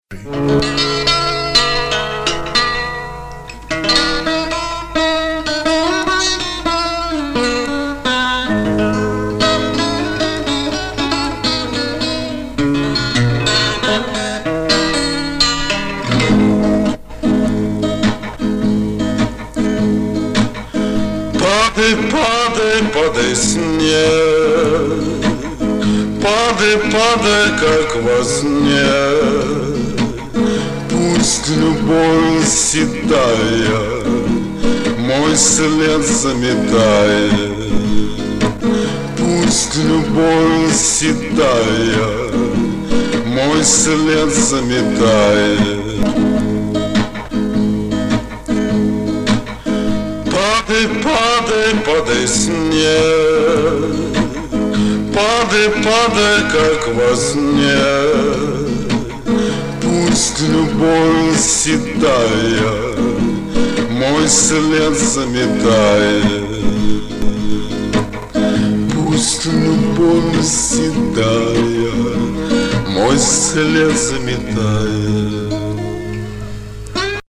Шикарное качество!